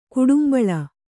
♪ kudumbaḷa